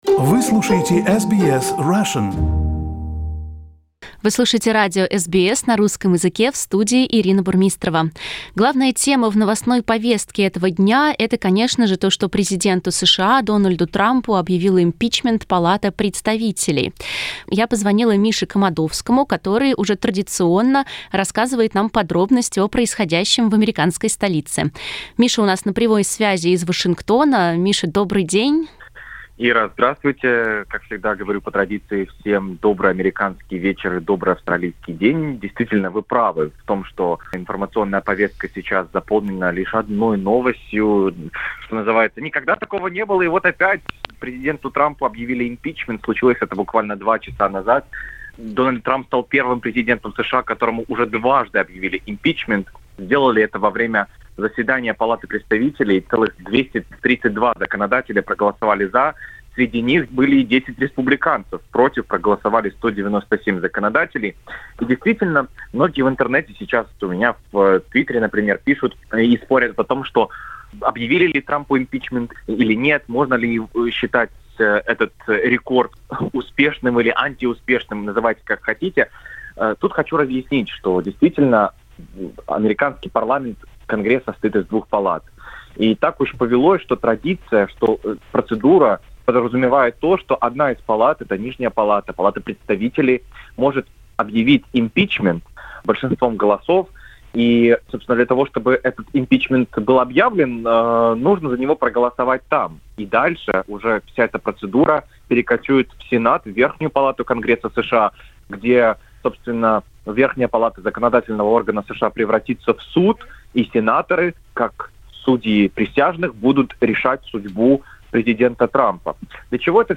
Второй импичмент Трампа - репортаж из Вашингтона